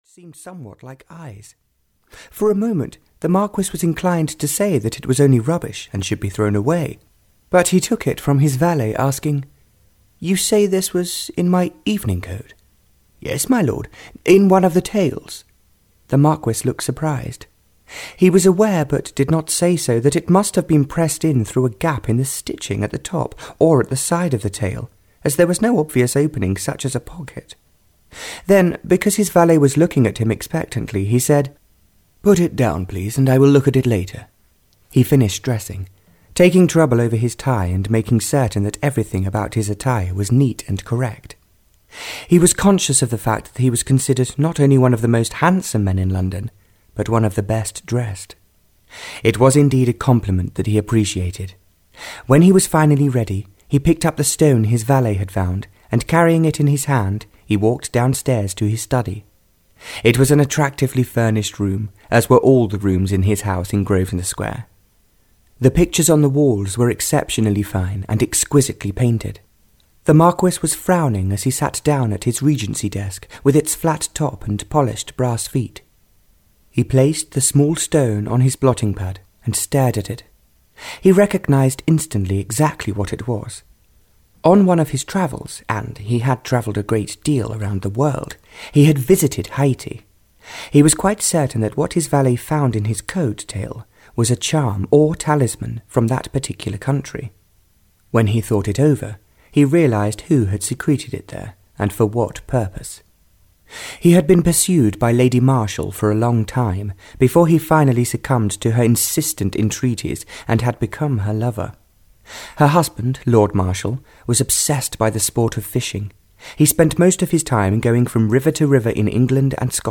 Audio knihaThe White Witch (Barbara Cartland's Pink Collection 23) (EN)
Ukázka z knihy